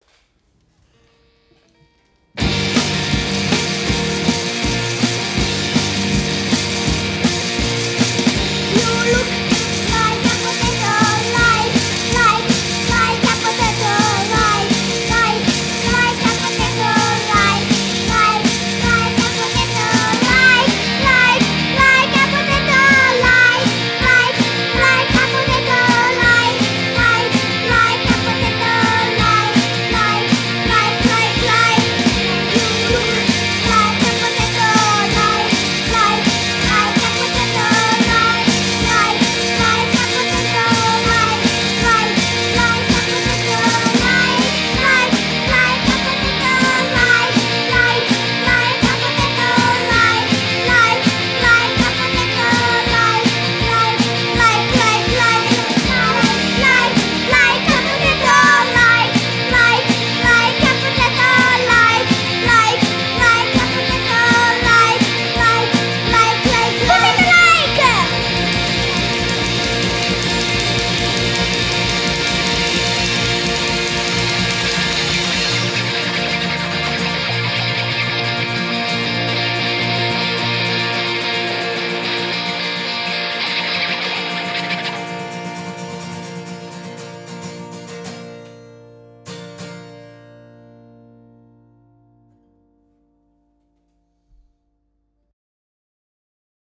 vocals
guitars, drums, bass